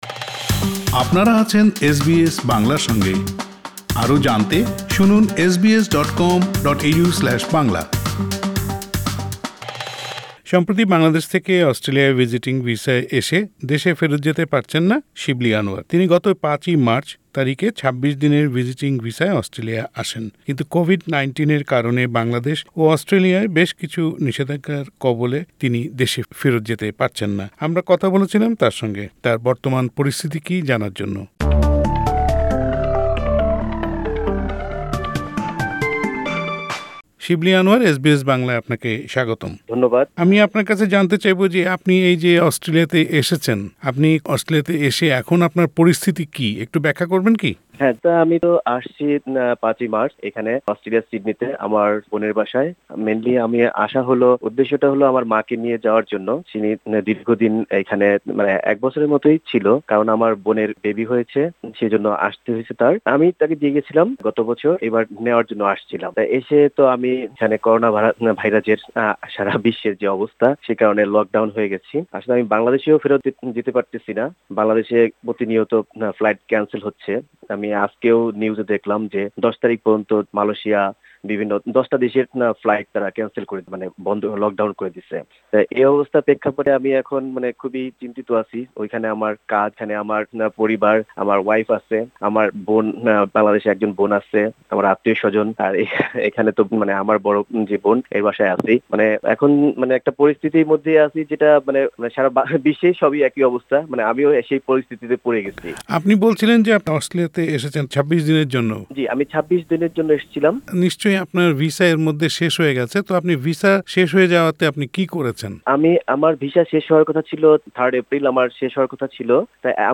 এস বি এস বাংলার সাথে তার বর্তমান অবস্থা নিয়ে কথা বলেছেন।